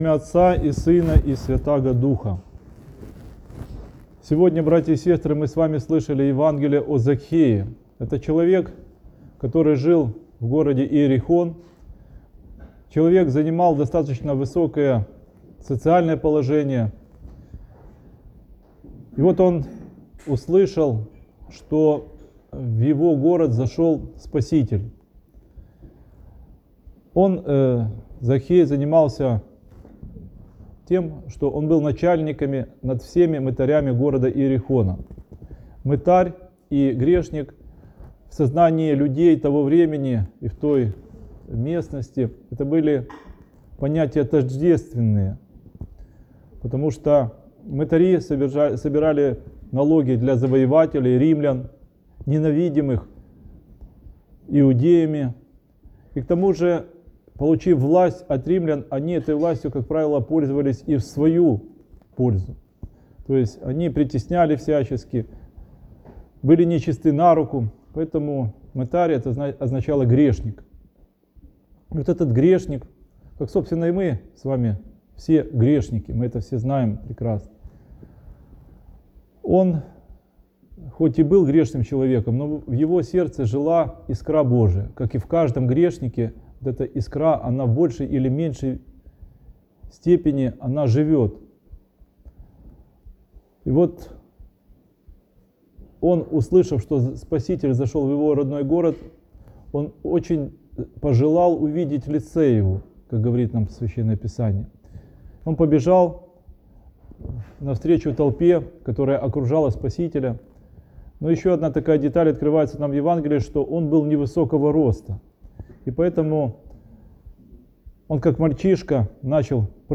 Сохранен как Проповеди